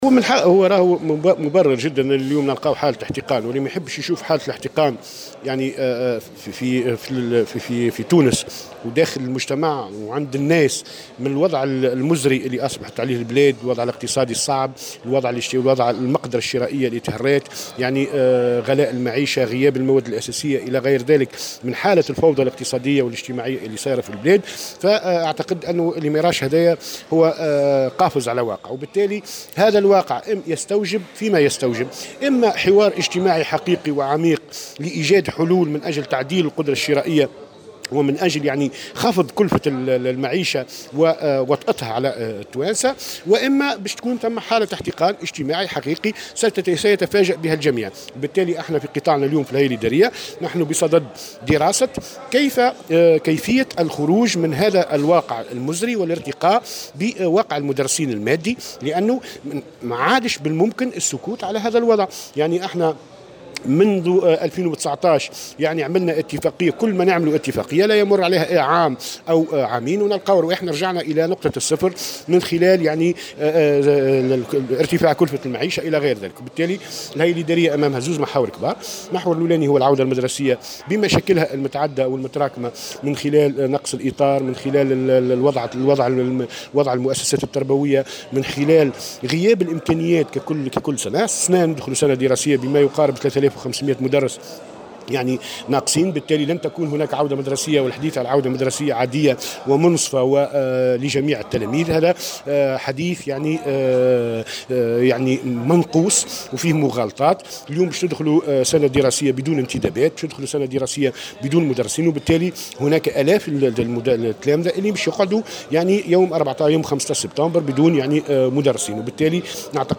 وأكد في تصريح لمراسلة الجوهرة أف أم أن عديد المشاكل المتفاقمة في قطاع التعليم الثانوي والتي يعاني منها المربون ستكون اليوم محور نقاش خلال هيئة ادارية للجامعة العامة للتعليم الثانوي بالحمامات، من بينها مستحقات المربين المتخلدة لدى الوزارة، إضافة إلى عمليات التنكيل والظلم التي تعرض إليها بعض المربين، إلى جانب الاكتظاظ في الأقسام ونقص عدد الأساتذة الذي بلغ 3500 استاذ خلال العودة المدرسية الحالية.